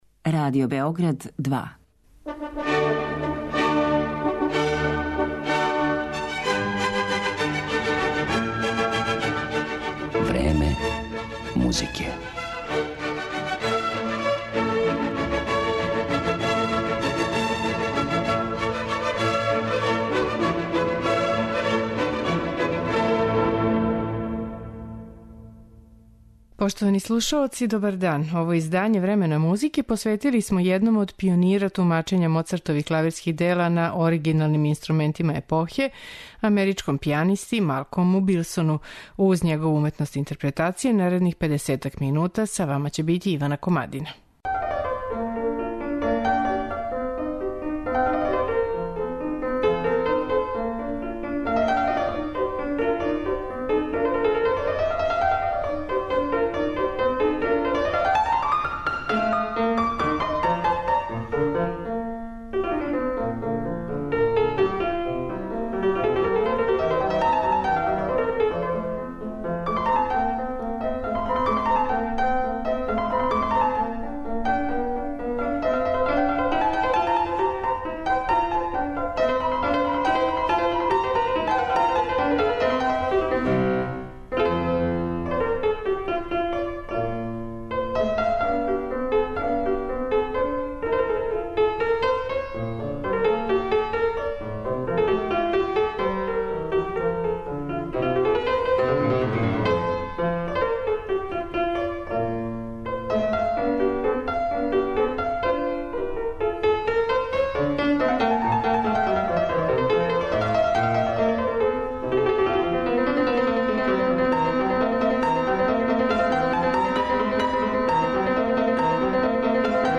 Представљамо америчког пијанисту Малколма Билсона.
У данашњем Времену музике, уметничку личност Малколма Билсона представићемо његовим интерпретацијама дела Волфгана Амадеуса Моцарта и Франца Шуберта.